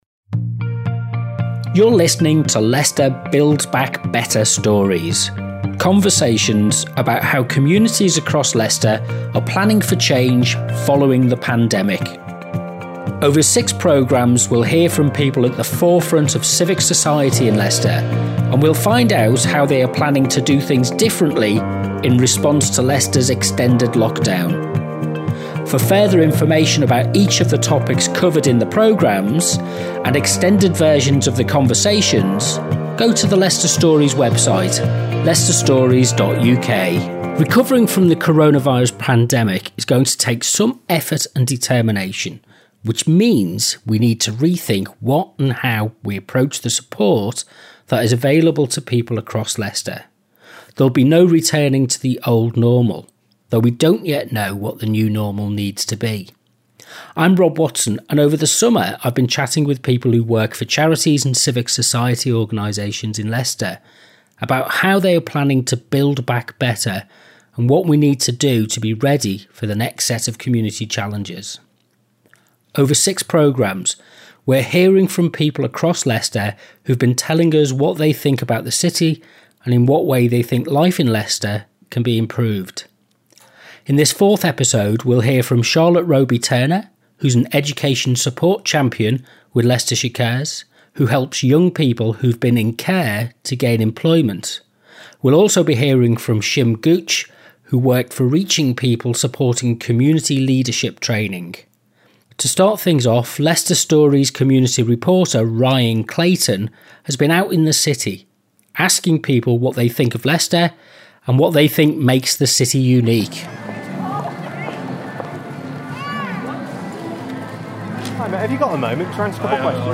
Interview 01